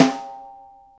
gretsch rim p.wav